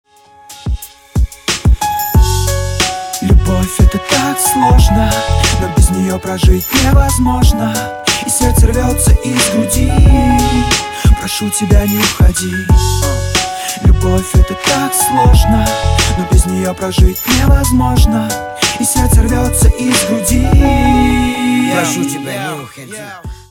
• Качество: 256, Stereo
спокойные
романтичные